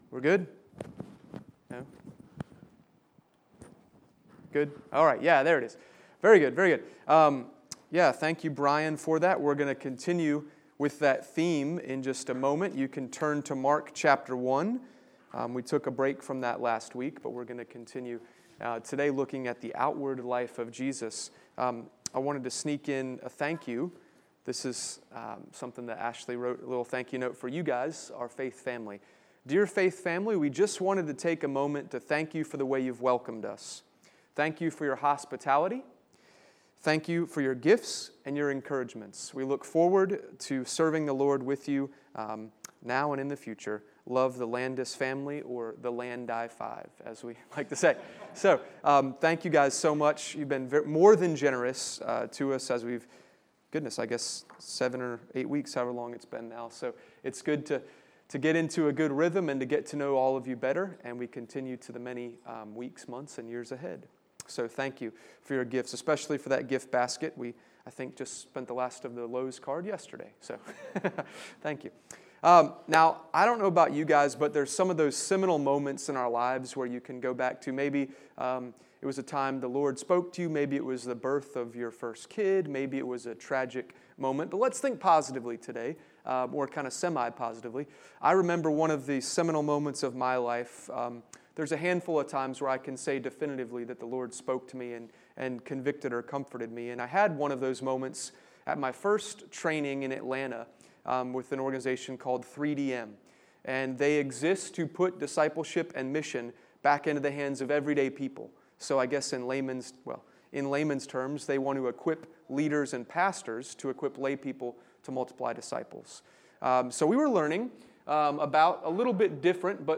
Sermon-9-13-20.mp3